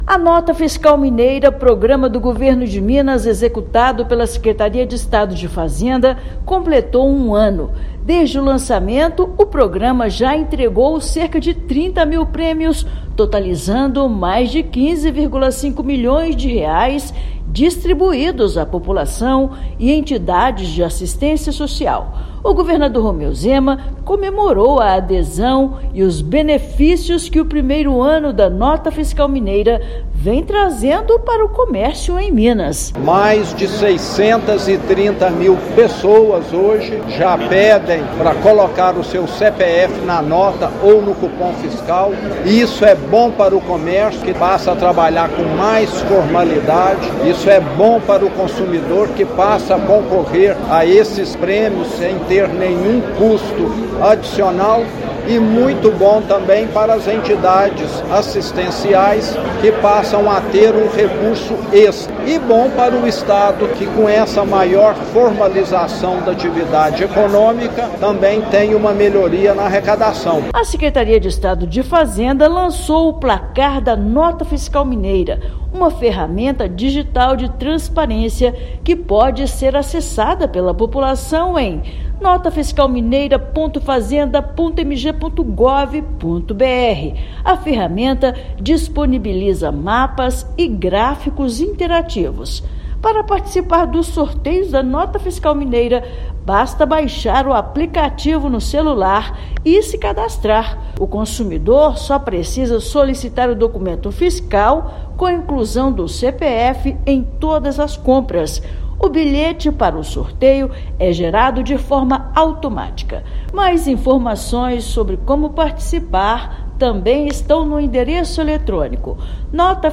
Já foram distribuídos 30 mil prêmios para consumidores e entidades de assistência social. Ouça matéria de rádio.